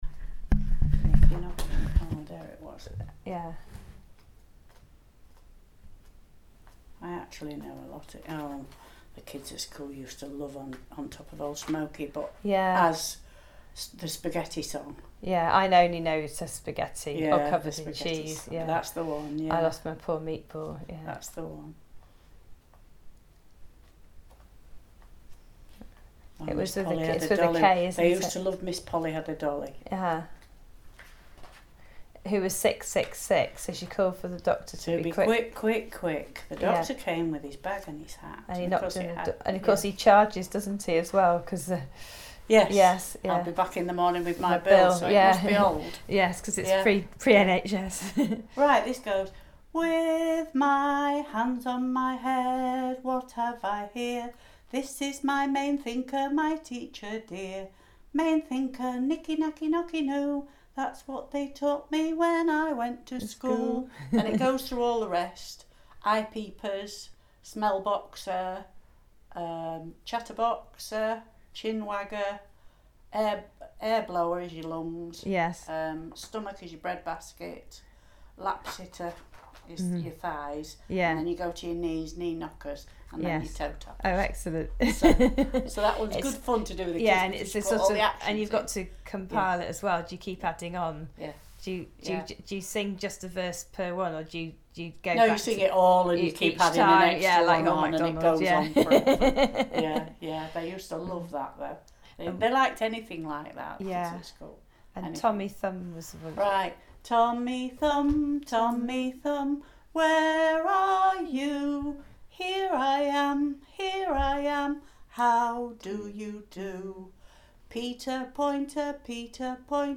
Lullaby_recording Pubblico